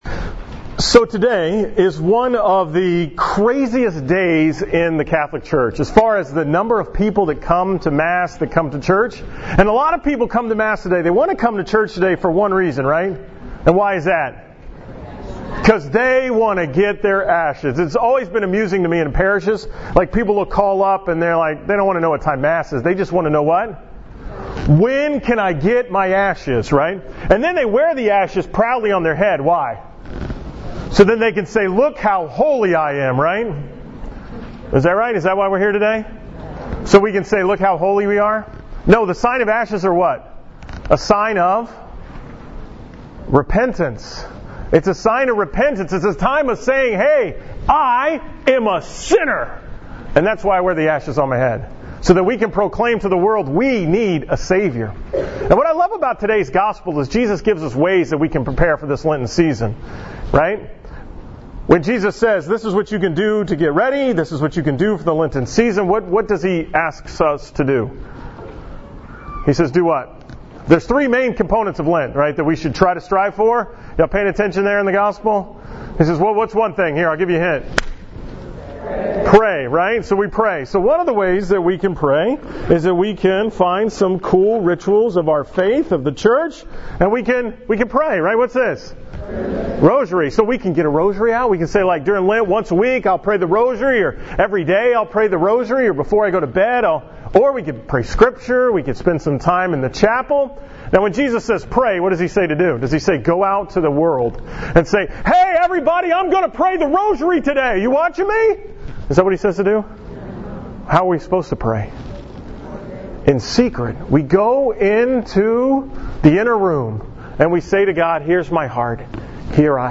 From the school Mass at Duchesne Academy on March 1, 2017